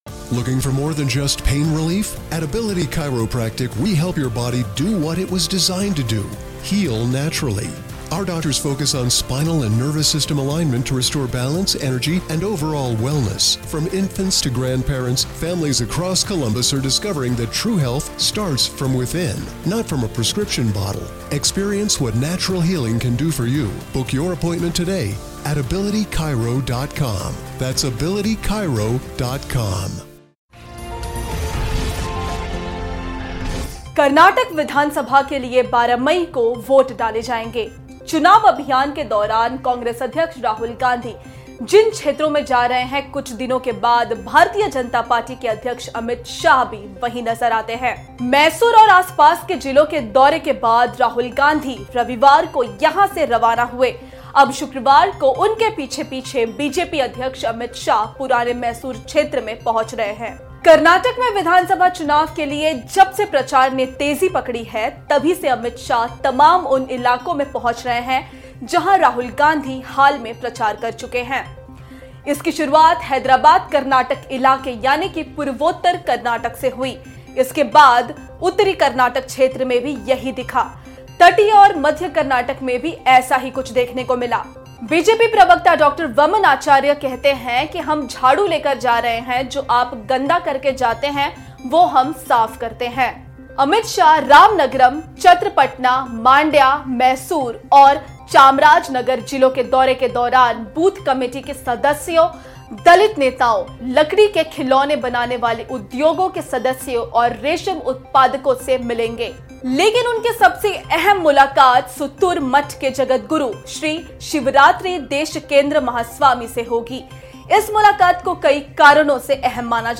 News Report / कर्नाटक विधानसभा चुनाव: आखिर क्यों राहुल गांधी का पीछा कर रहे है अमित शाह ?